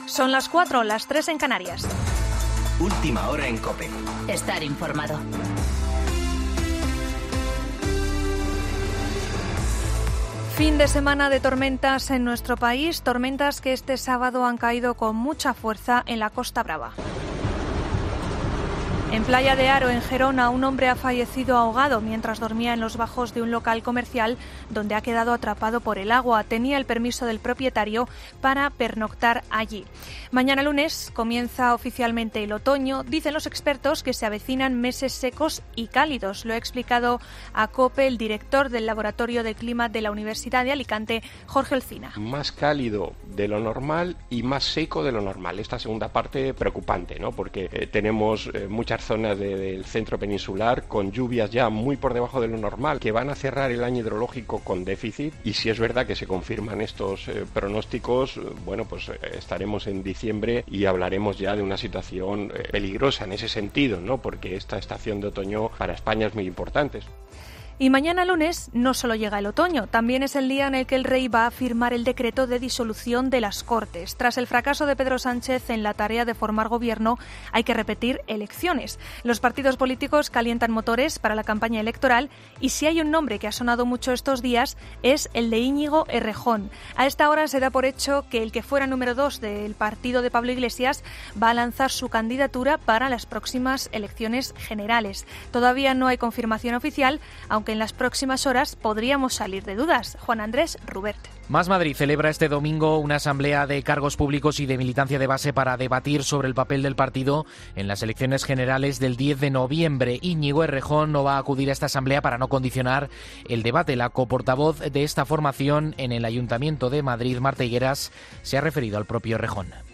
Boletín de noticias COPE del 22 de septiembre a las 04.00